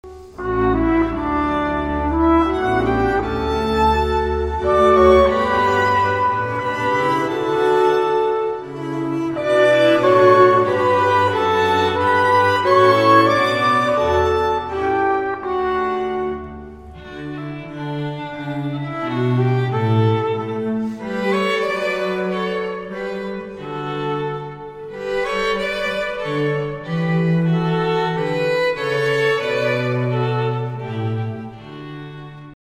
Trompete
Violine
Viola
Violoncello
Contrabass
in der Auferstehungskirche Neu-Rum